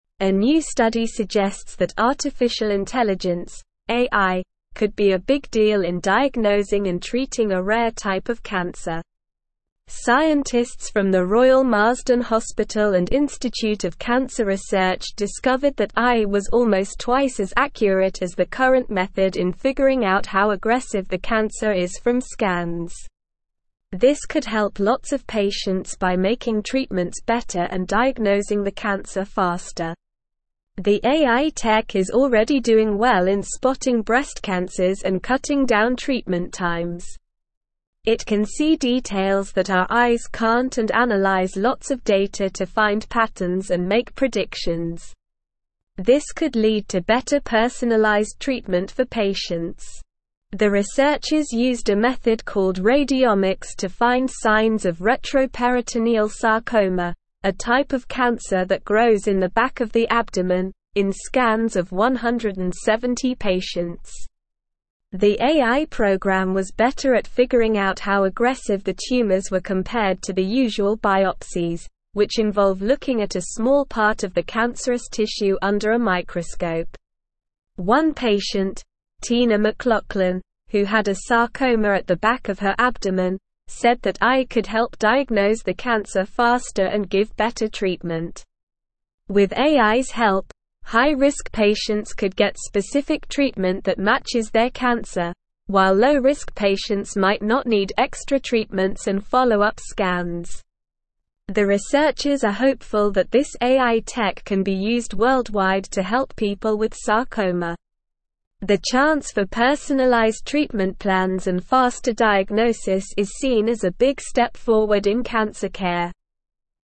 Slow
English-Newsroom-Upper-Intermediate-SLOW-Reading-AI-Shows-Promise-in-Grading-Aggressiveness-of-Rare-Cancer.mp3